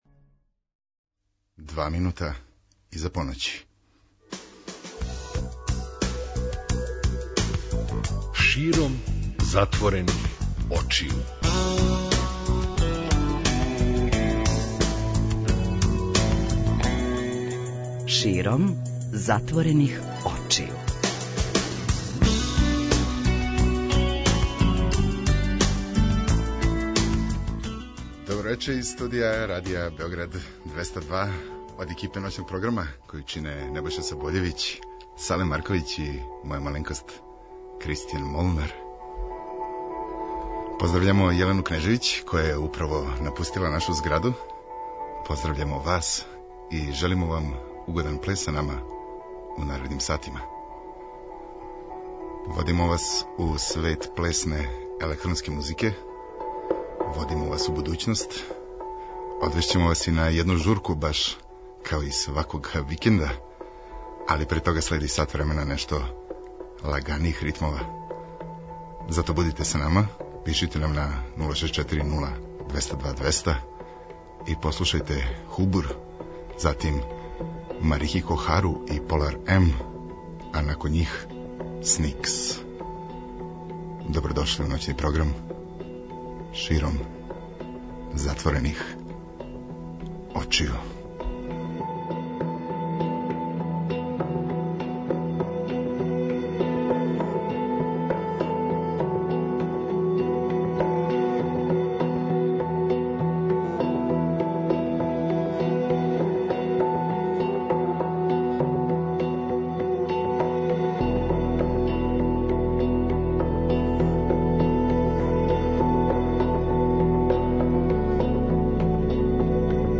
преузми : 55.40 MB Широм затворених очију Autor: Београд 202 Ноћни програм Београда 202 [ детаљније ] Све епизоде серијала Београд 202 Тешке боје Пролеће, КОИКОИ и Хангар Устанак Устанак Устанак